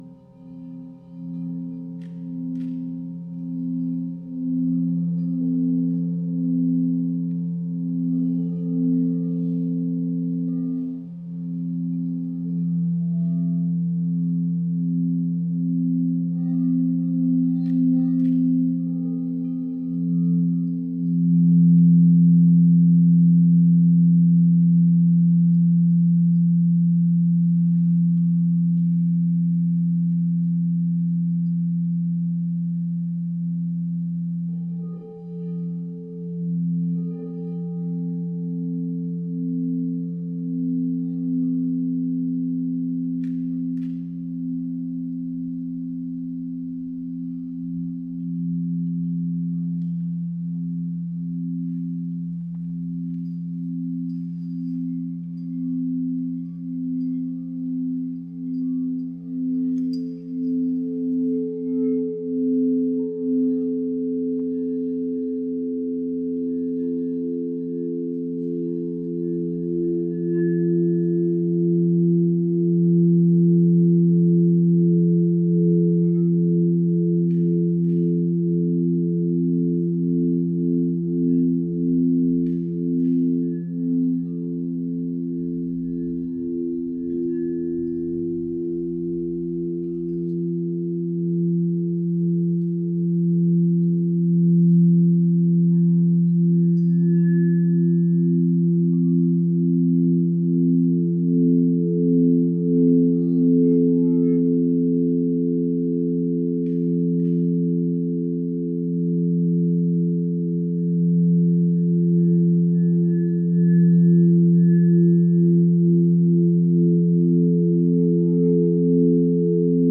bols chantants
Laissez les bols et les instruments masser vos cellules et apaiser votre système nerveux.
Extrait bain sonore en live